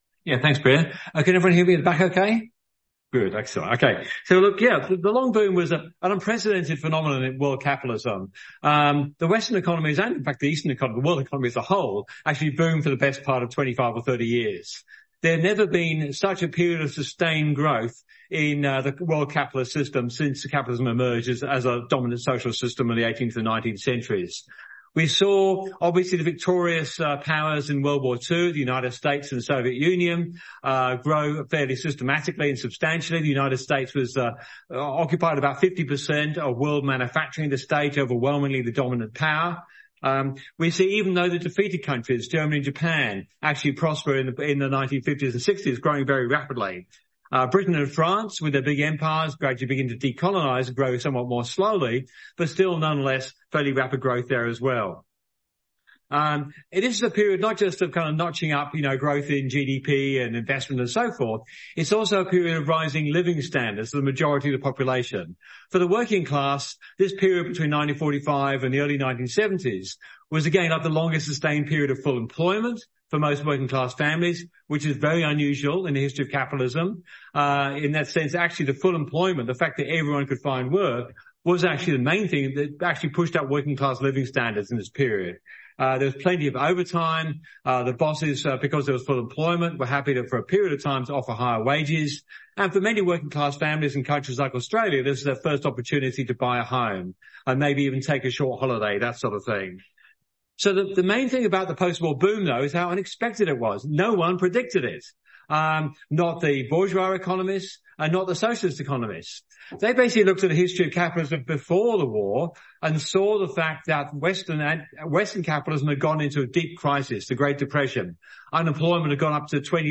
Revolution 2023 Play talk Download For three decades after the end of World War II, the world economy boomed like never before, with most economies more than tripling in size.